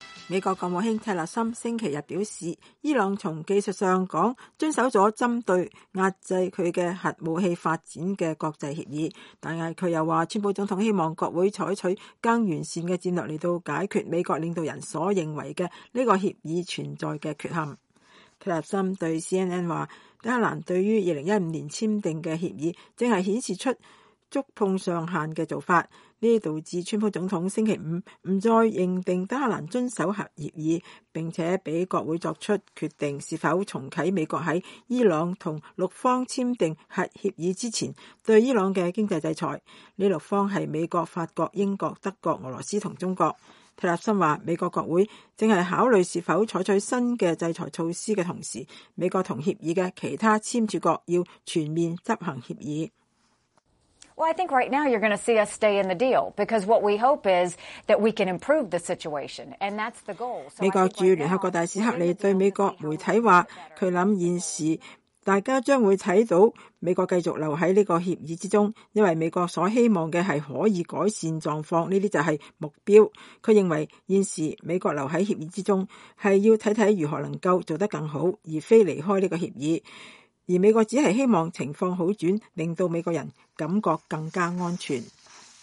美國國務卿蒂勒森星期五在國務院講話。